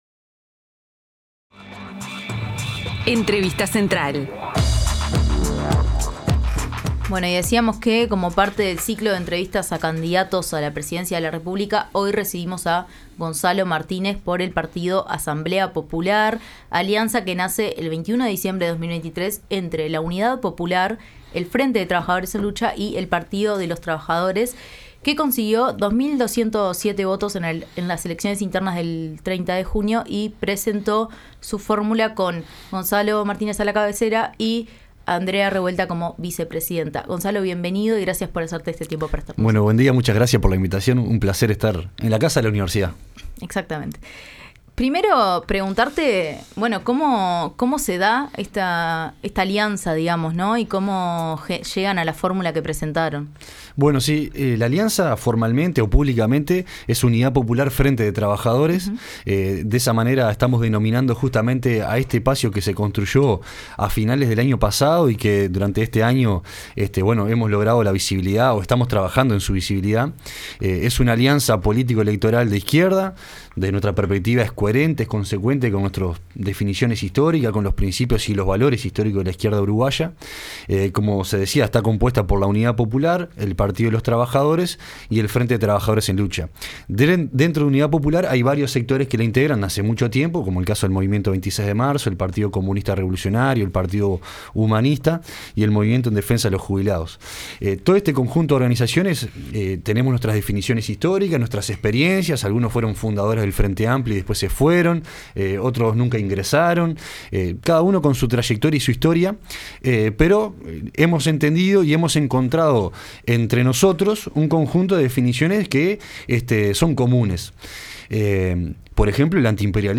Continuamos con el Ciclo de entrevistas a Candidatos a la Presidencia de la República.